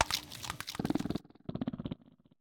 Cri de Lestombaile dans Pokémon Écarlate et Violet.